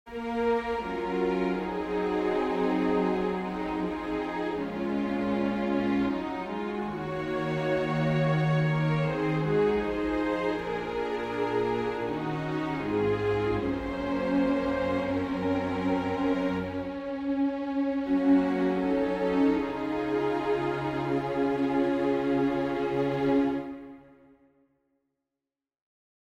Key written in: D♭ Major
How many parts: 4
Type: Barbershop
All Parts mix: